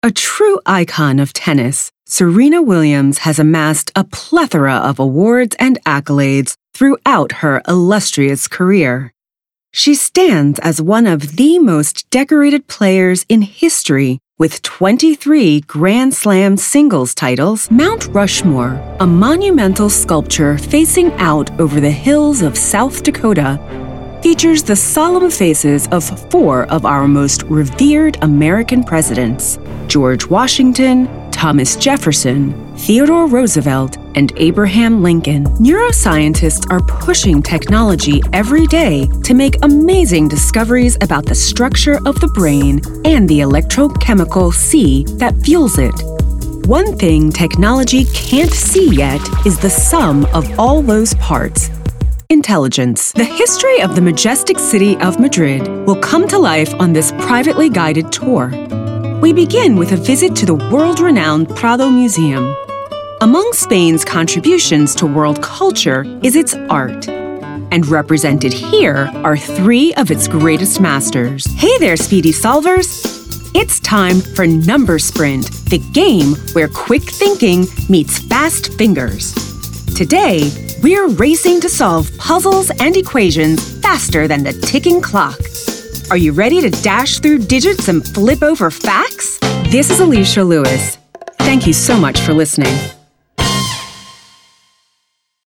Precise, articulate delivery.
Narrative Reel (95s)
- Calm, credible, and grounded vocal presence
- Treated home booth